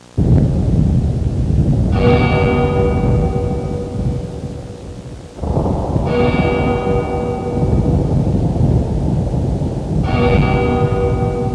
Foreboding Bells Intro Sound Effect
Download a high-quality foreboding bells intro sound effect.
foreboding-bells-intro.mp3